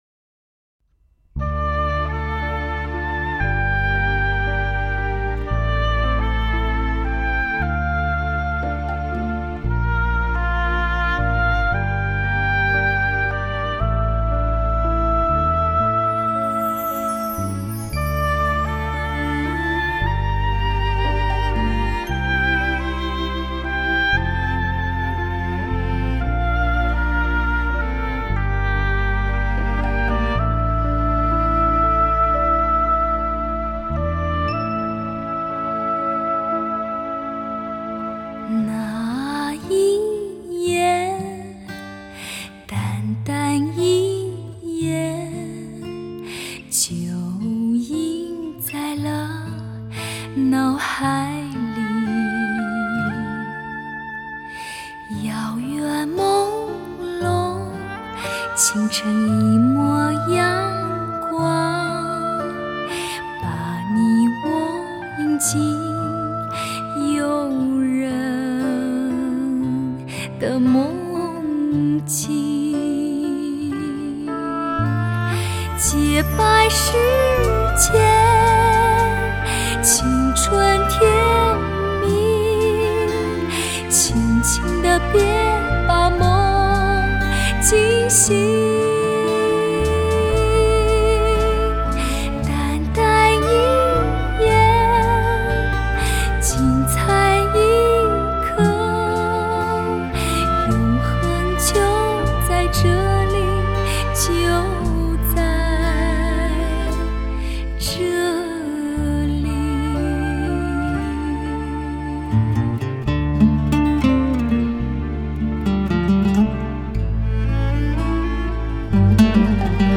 唱法中没有刻意的装饰，属于直抒胸臆，而不矫揉造作，情也浓，但不是激情，是温情